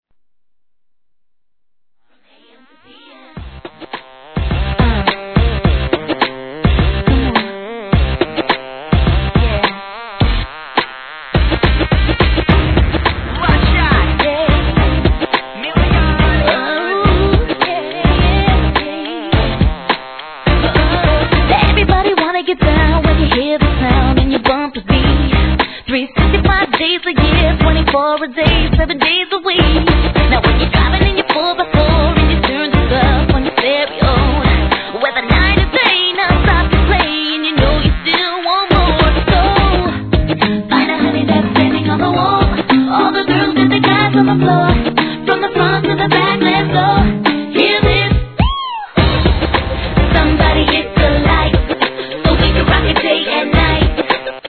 HIP HOP/R&B
キャッチーなプロダクションにより若さを感じる歌いっぷりがキュート☆人気曲♪